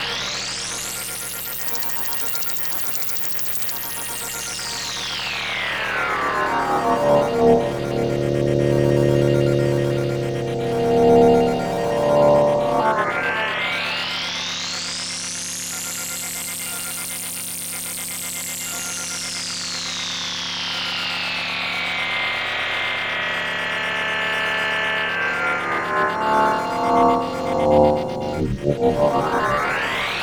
Track 10 - Arp Pad.wav